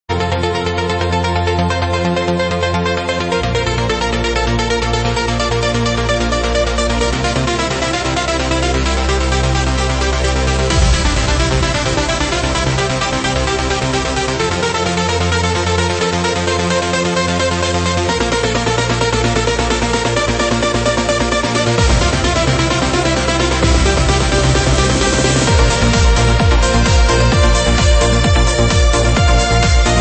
Uplifting Trance mix